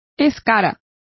Complete with pronunciation of the translation of slough.